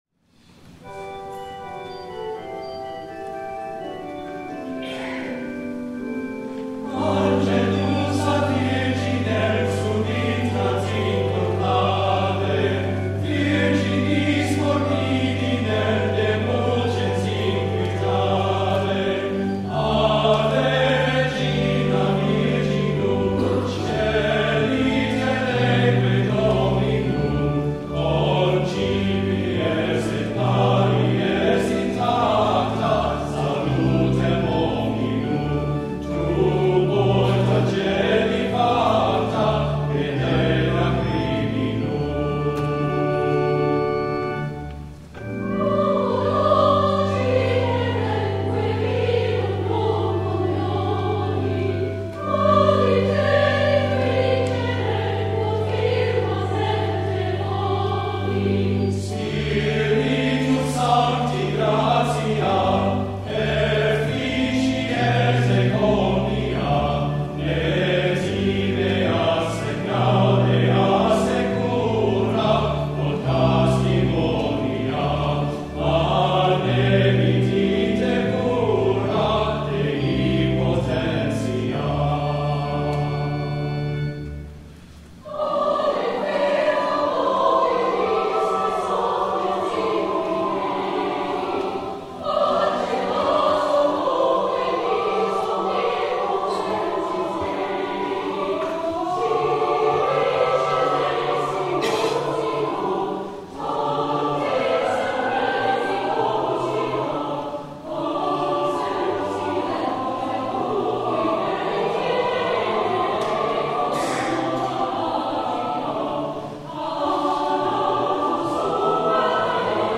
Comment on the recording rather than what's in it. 8 P.M. WORSHIP